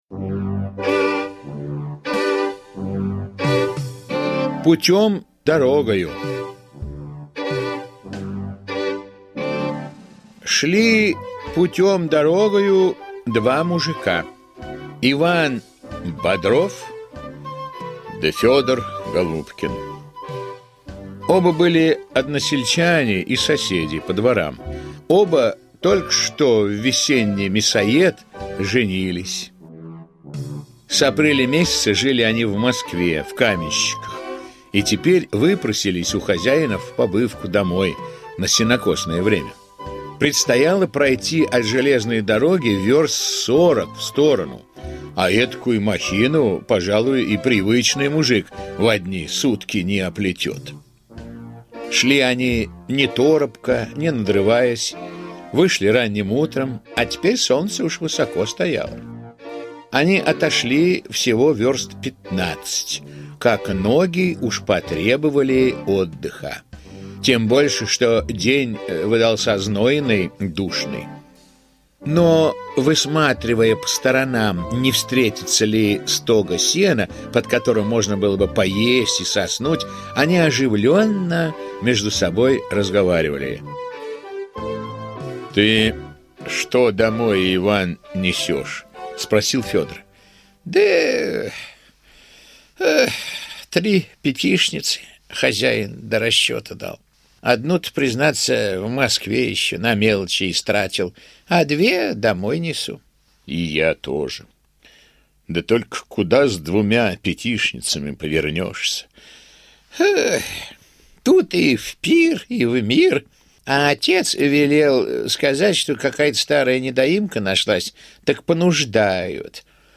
Путем-дорогою - аудиосказка Михаила Салтыкова-Щедрина - слушать онлайн